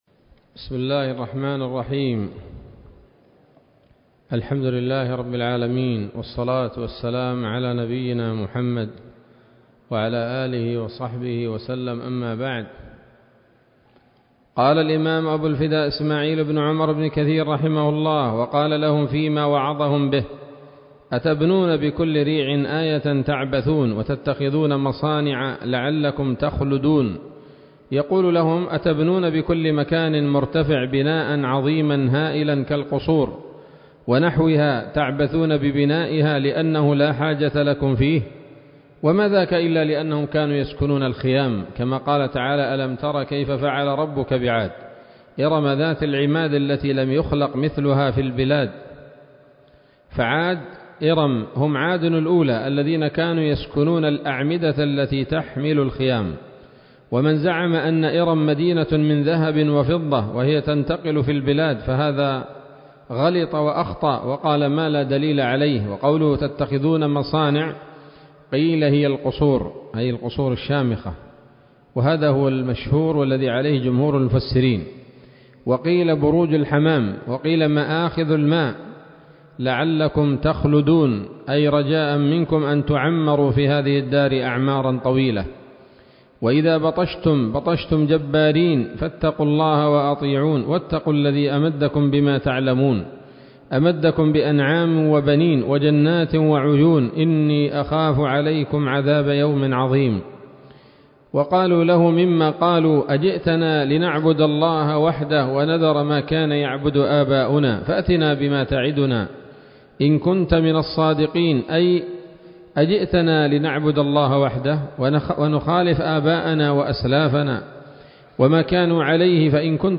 الدرس الحادي والثلاثون من قصص الأنبياء لابن كثير رحمه الله تعالى